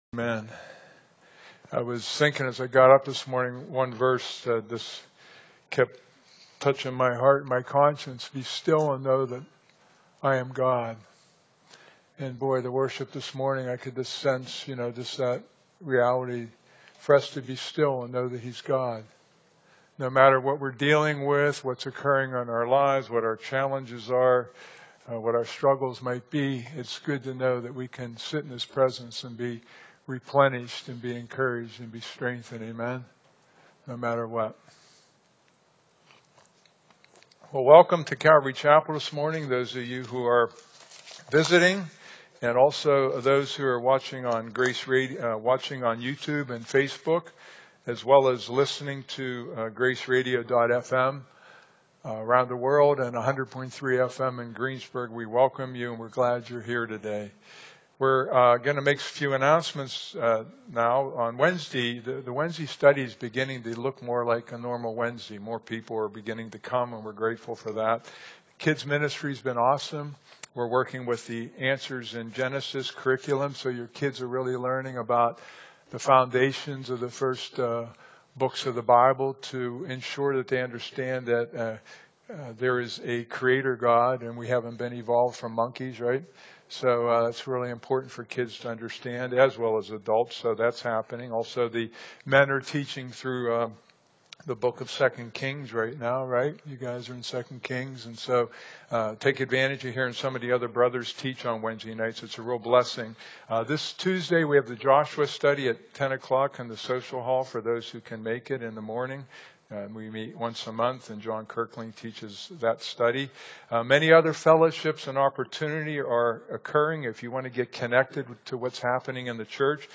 Today’s teaching specifically covers four incidents regarding the healing ministry of Jesus. In each of these situations, Jesus healed people from all different conditions that were humanly impossible to recover from.